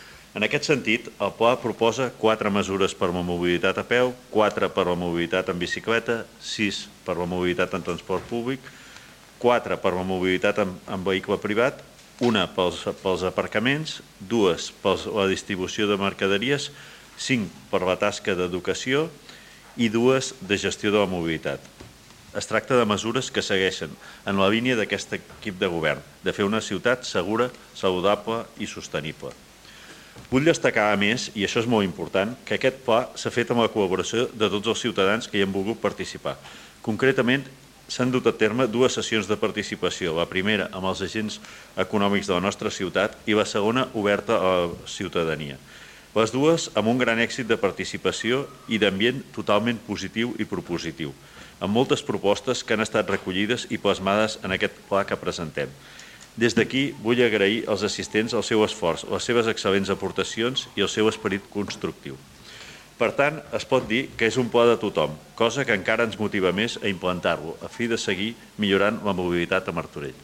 PLe Municipal. Juliol de 2025
Lluís Sagarra, regidor de Seguretat Ciutadana i Mobilitat